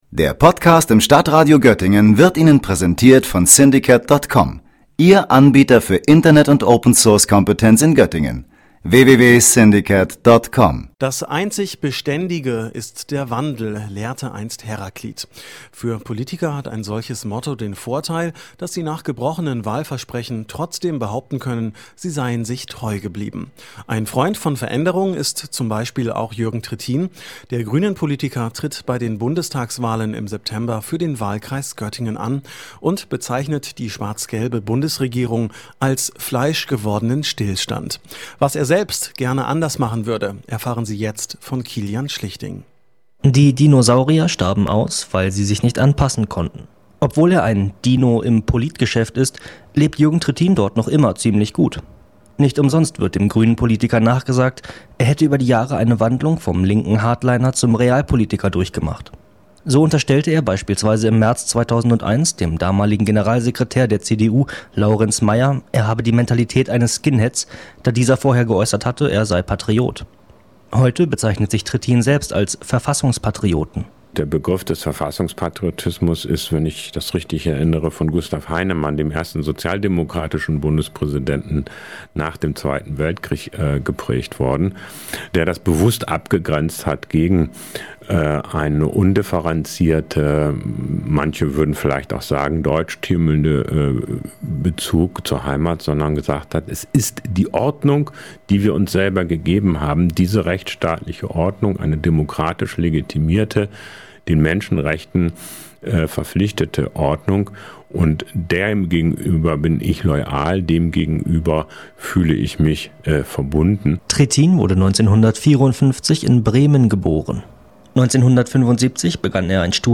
Beiträge > Porträt des Bundestagskandidaten Jürgen Trittin (Grüne) - StadtRadio Göttingen